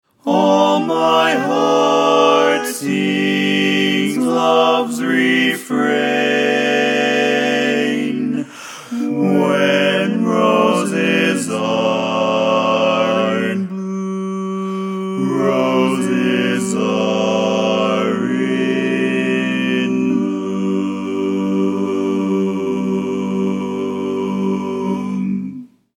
Key written in: G Major
How many parts: 4
Type: Barbershop
All Parts mix:
Learning tracks sung by